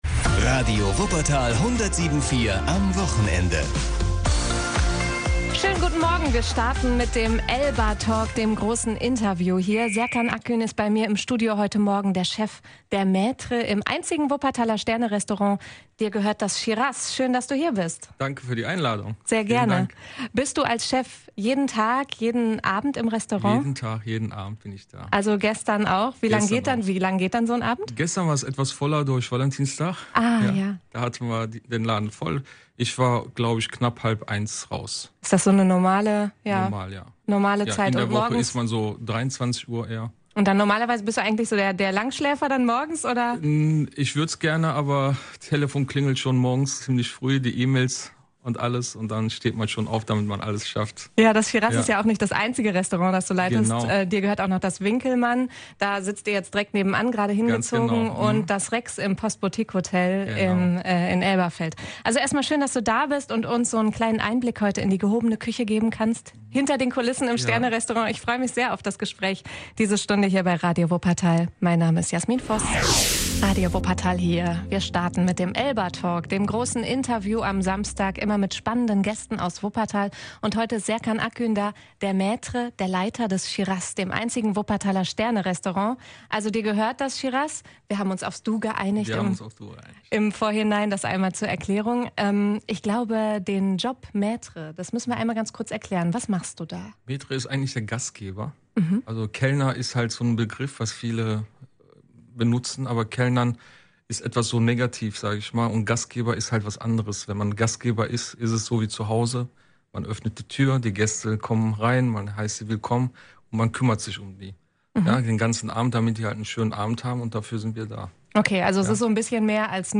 Der Trend sei, offener für alle zu sein, auch wenn das seinen Preis hat. Das erklärt er im Interview, hört hier rein!